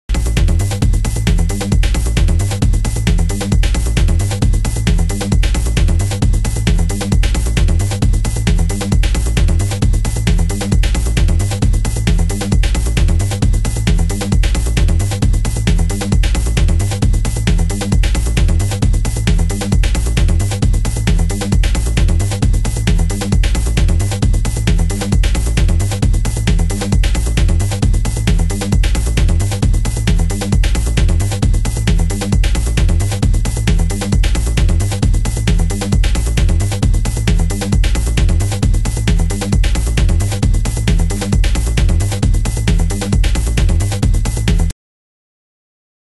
◎A1 & B1 are locked grooves!!